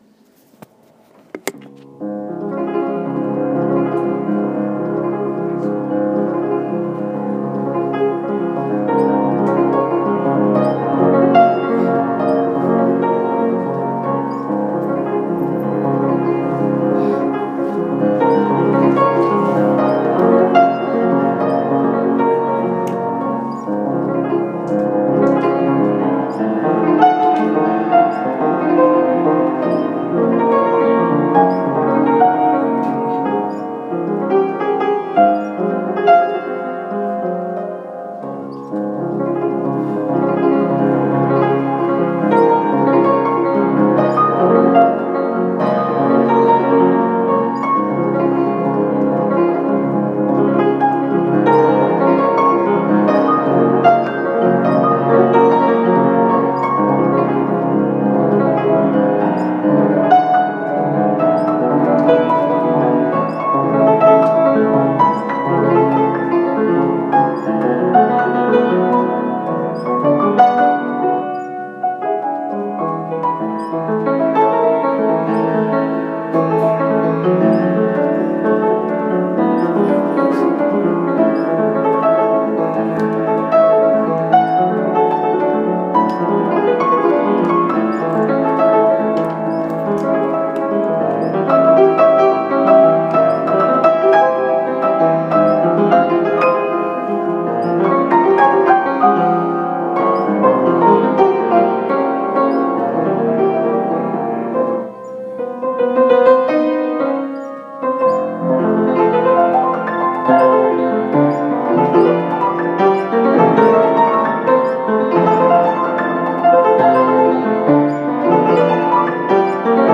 :O All recorded by my supah-fancy (hah) phone!
(Sad fact of life: I will always be my worst critic when it comes to my abilities in piano.